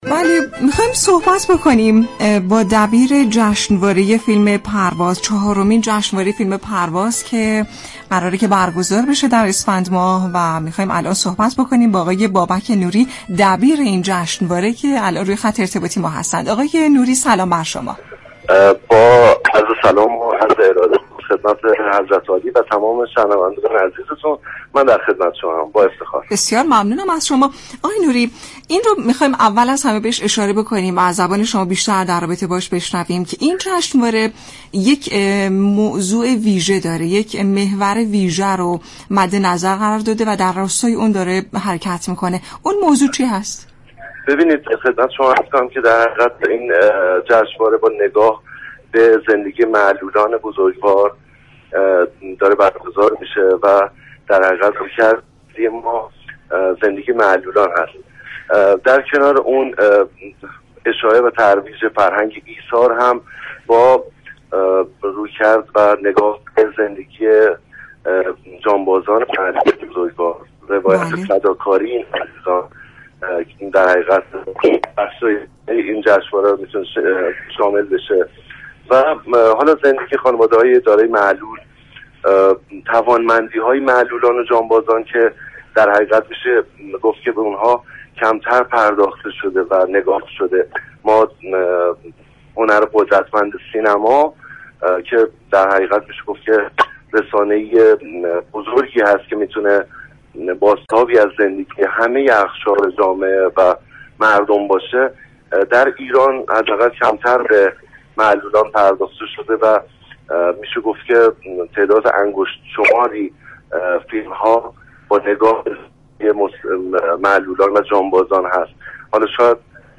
در برنامه «حوض نقره» رادیو ایران